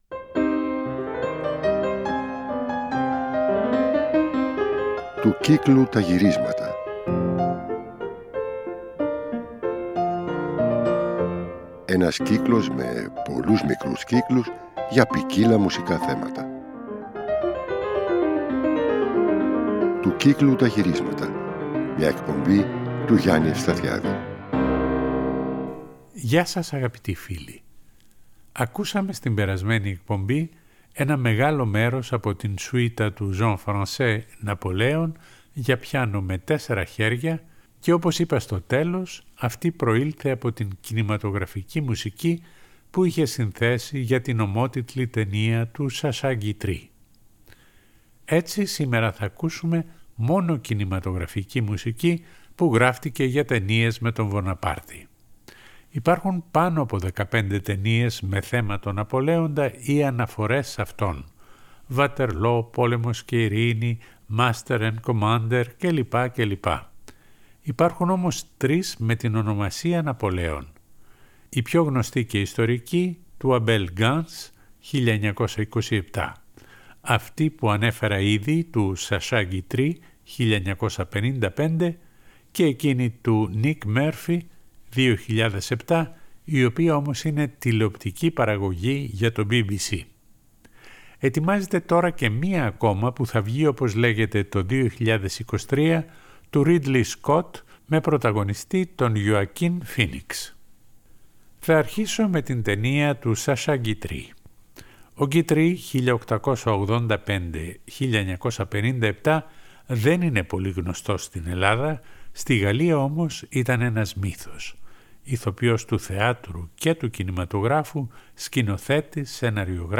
Όλη η εκπομπή είναι αφιερωμένη σε κινηματογραφικές μουσικές